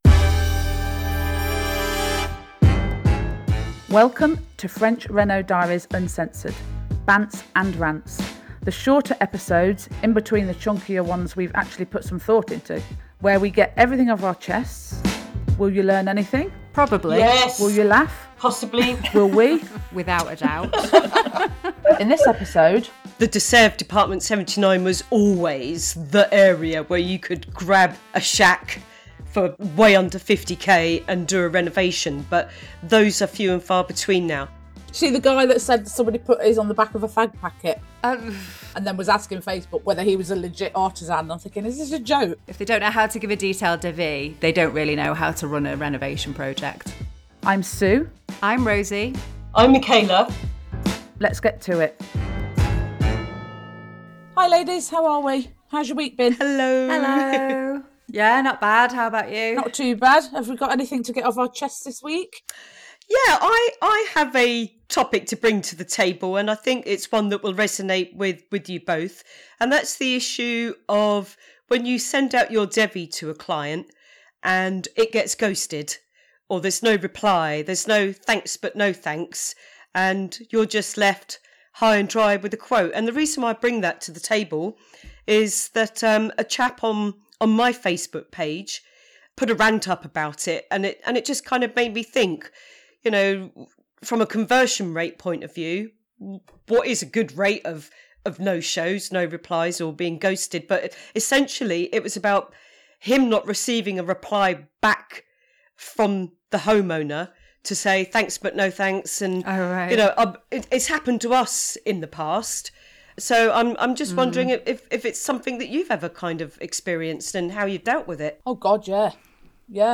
In this Rants & Bants episode (our shorter, more spontaneous, but always - okay, mostly - reno focused chats), we get all het up about everything from being ghosted after sending detailed quotes, to Facebook “expert” opinions on pricing, to the realities of buying and flipping property in today’s French property market. Most importantly though, we discuss Mr Kipling cakes and Burger King. Plus, the chic brackets we're importing from Poland...